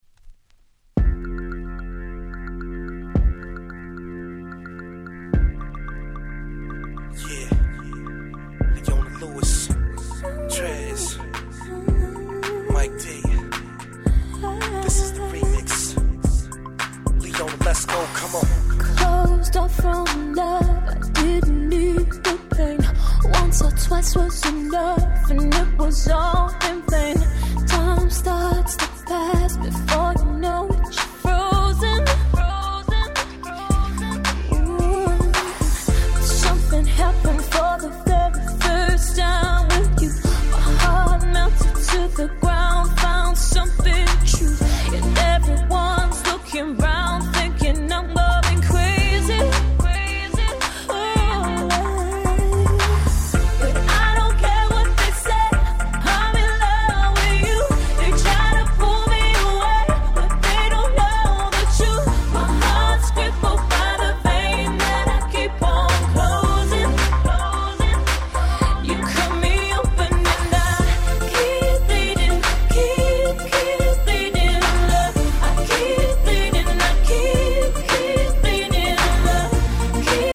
(試聴ファイルは別の盤から録音してございます。)
08' 世界的大ヒットR&B♪